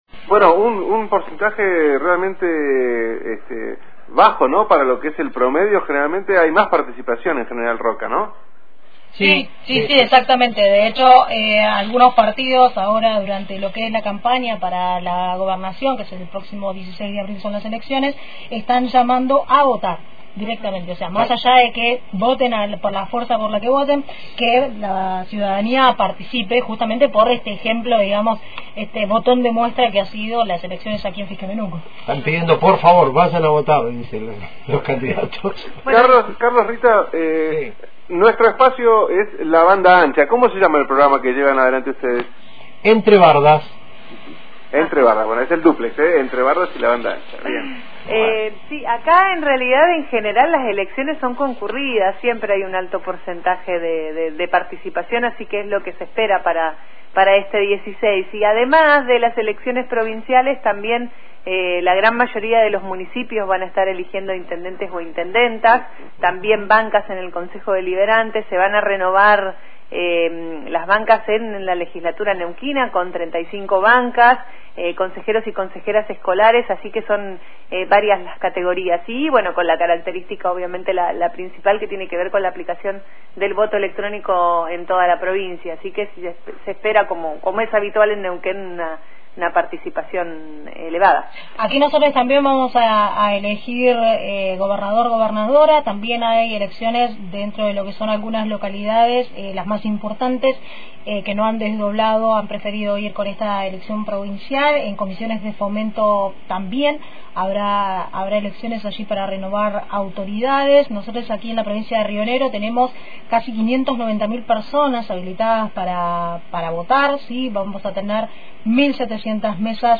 En la mañana de Antena Libre 89.1 compartimos un Duplex entre los programas «Banda Ancha» de Radio Universidad Calf y «Entre Bardas» para conversar y compartir todo sobre las próximas elecciones en la provincia de Neuquén y Río Negro.